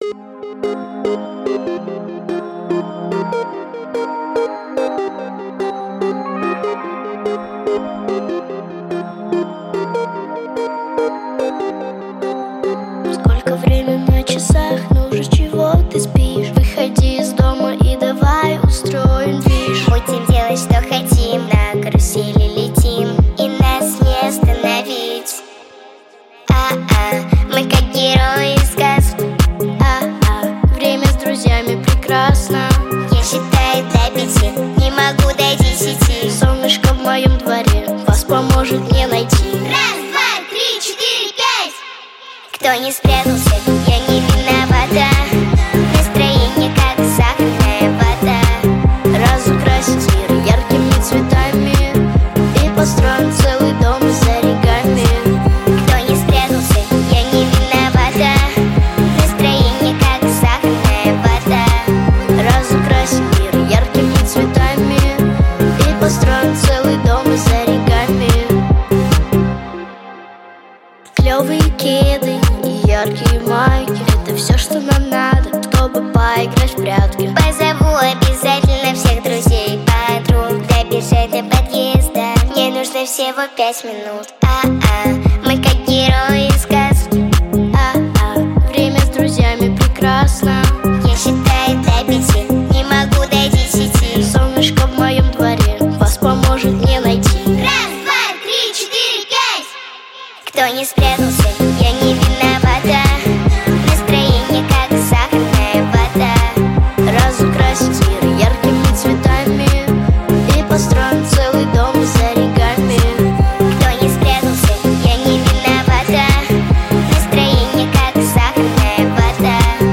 • Категория: Детские песни
детская дискотека